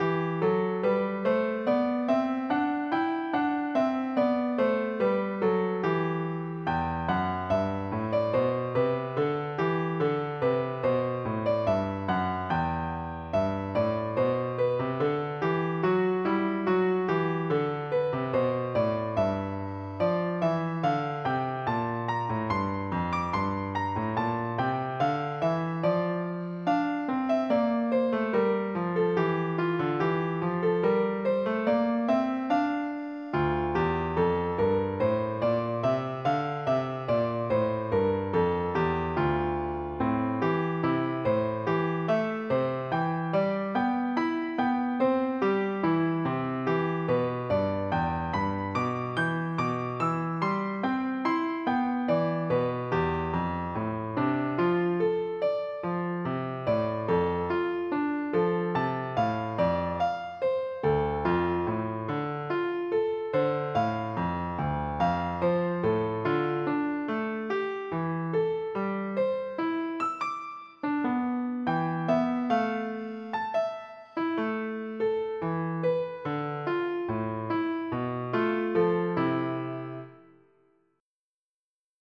mp3-Aufnahme: mit midi Instrument